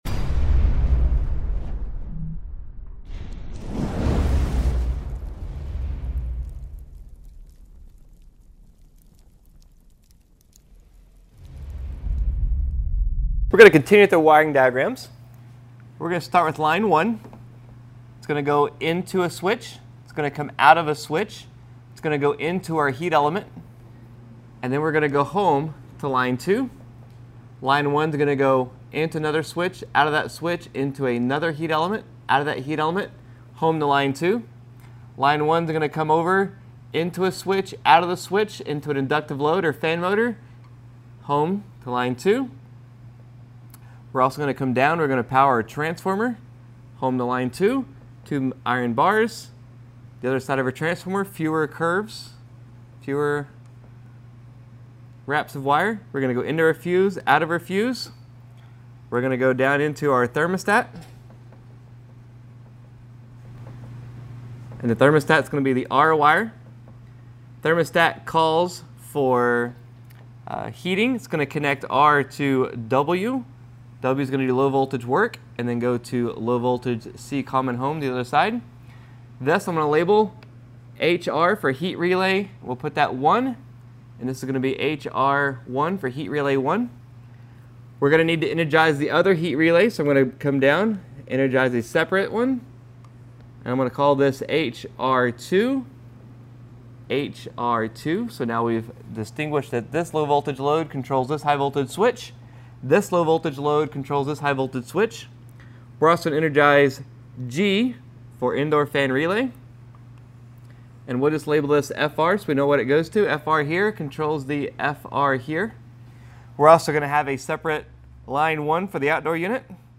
🛠 P9 - Understanding HVAC Wiring Diagrams Jan 6, 2025 Lecture Notes: Wiring Diagrams for HVAC Systems Overview Focus on wiring diagrams for HVAC systems. Explanation of the flow and connections for various components.